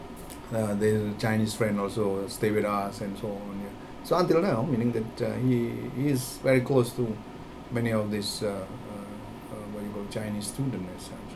S1 = Taiwanese female S2 = Indonesian male Context: They are talking about the Chinese friends S2's son made when he was in school.
These words are spoken quite fast, but otherwise there is nothing unexpected in the pronunciation.